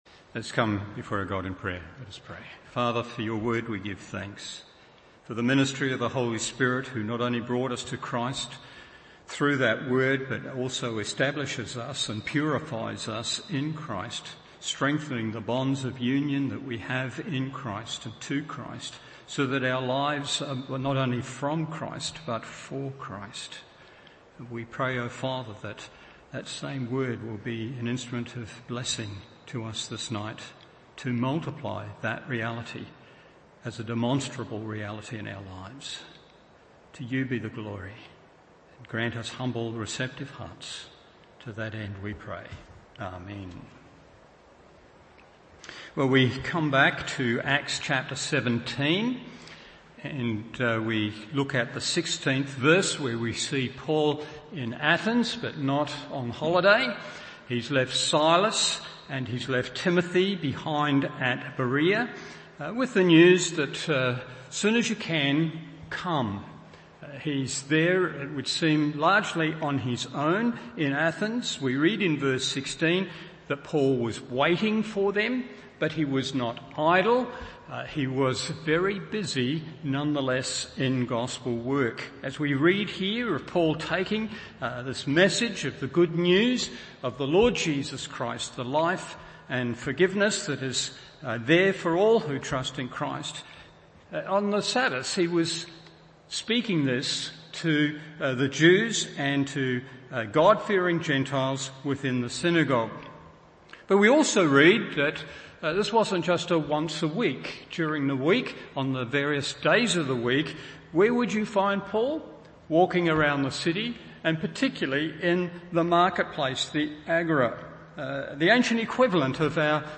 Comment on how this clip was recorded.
Evening Service Acts 17:16-34 1. The Idolatry 2. The Ignorance 3.